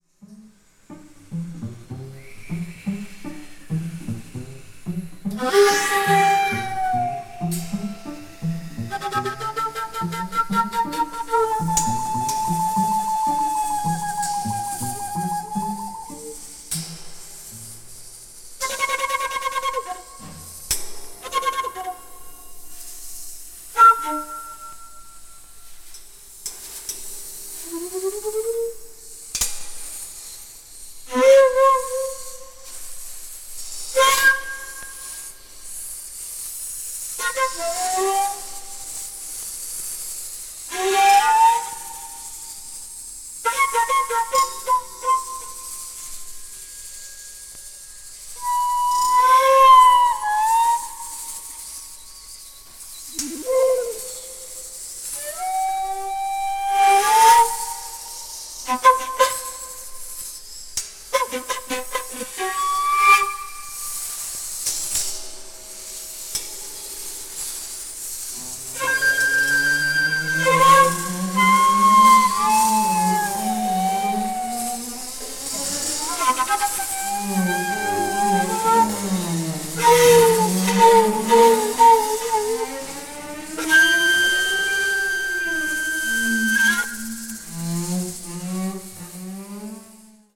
media : EX/EX(some slightly noises.)
a shakuhachi player of the Tozan school